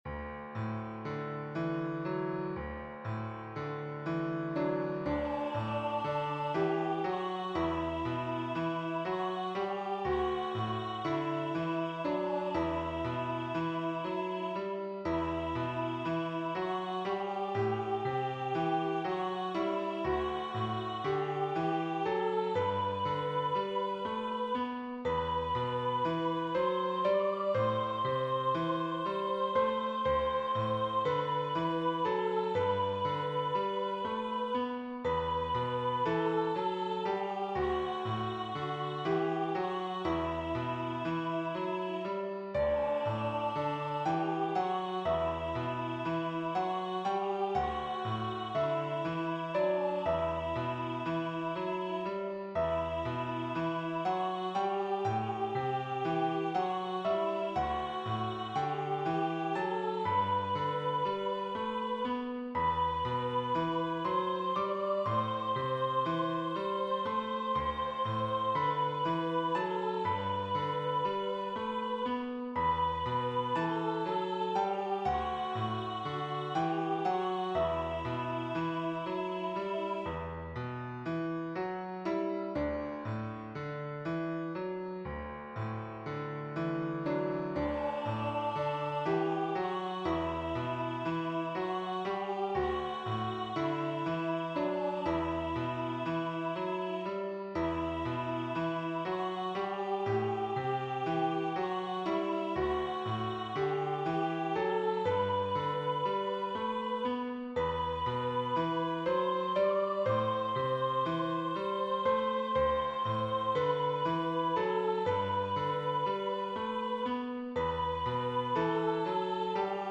A simple unison anthem with piano or organ accompaniment.